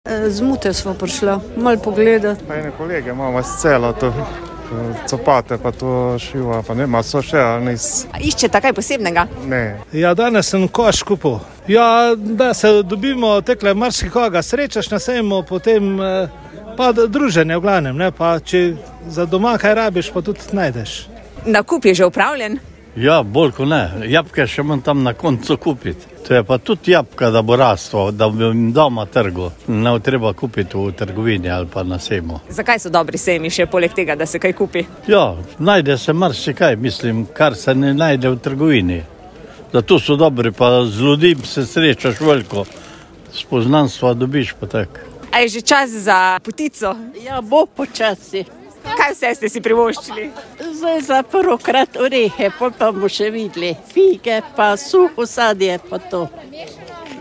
Kaj pravijo obiskovalci sejma: